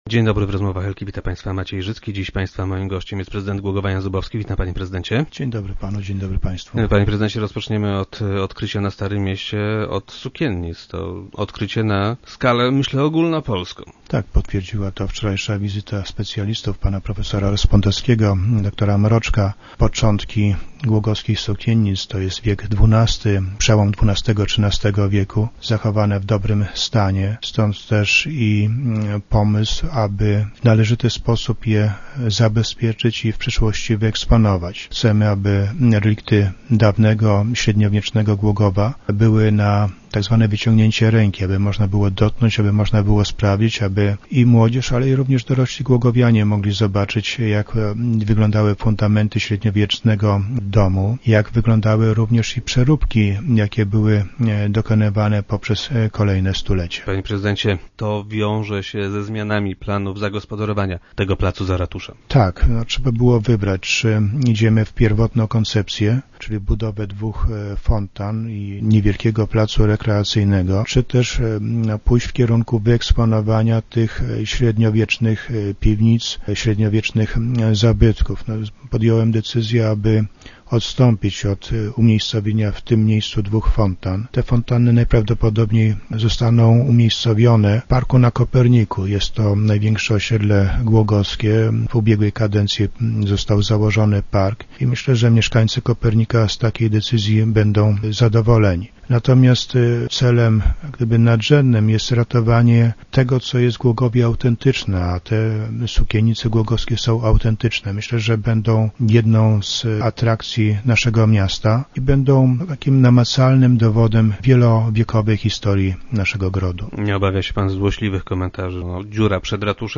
Władze miasta chcą by stały się one atrakcją turystyczną. - Na pewno będziemy chcieli odpowiednio wyeksponować te relikty średniowiecznego miasta - twierdzi Jan Zubowski, prezydent Głogowa i gość dzisiejszych Rozmów Elki.